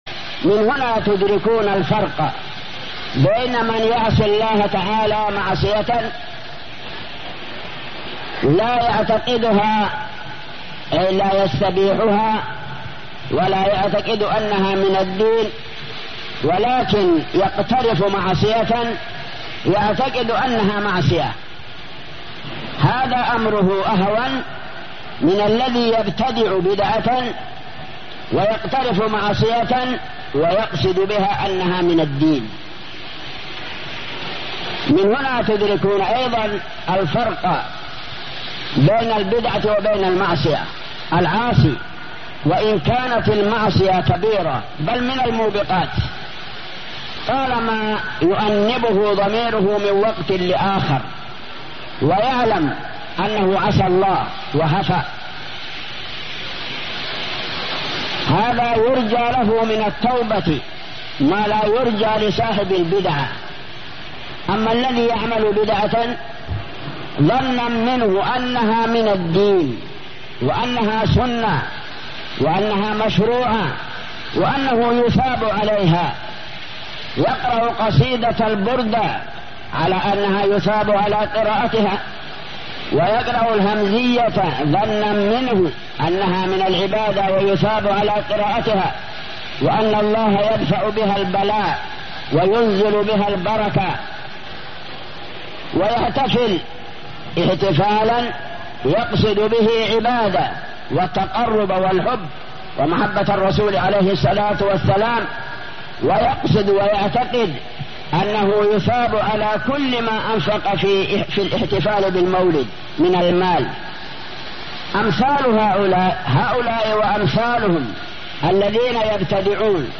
Format: MP3 Mono 44kHz 32Kbps (CBR)